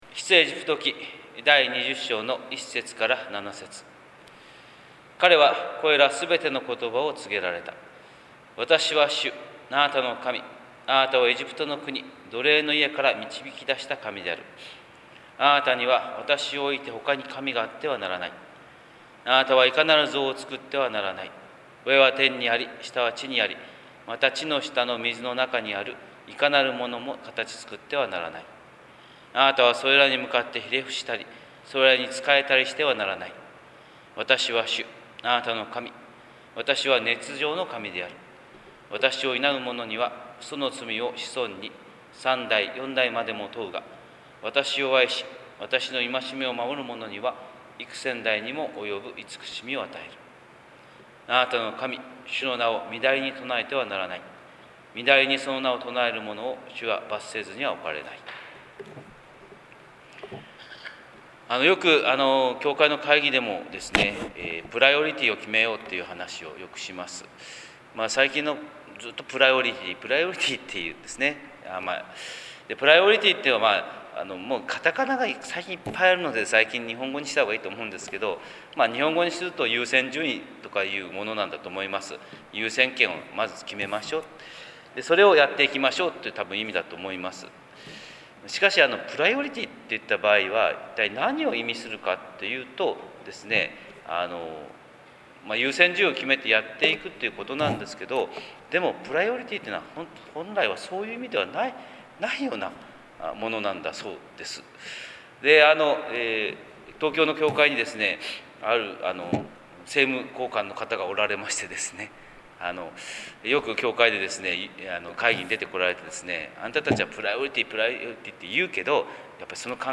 神様の色鉛筆（音声説教）
朝礼拝150227